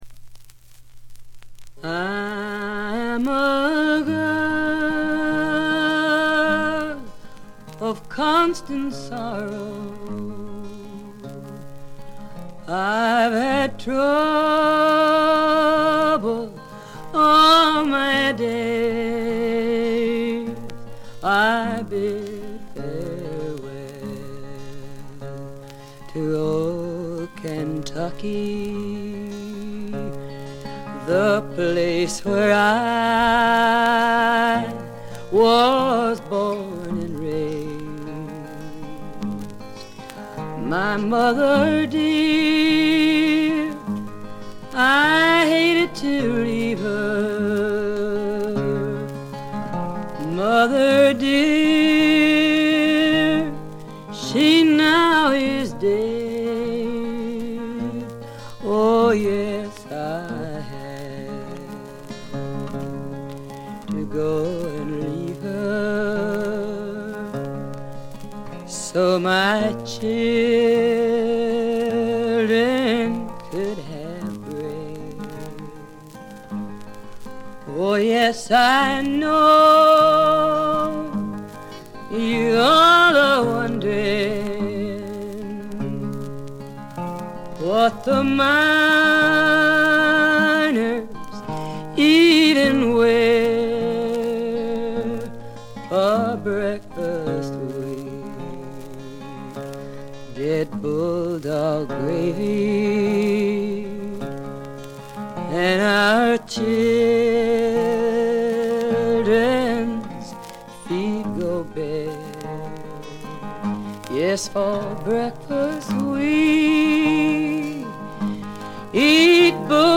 バックグラウンドノイズ、チリプチは常時大きめに出ます。
存在感抜群のアルト・ヴォイスが彼女の最大の武器でしょう。
試聴曲は現品からの取り込み音源です。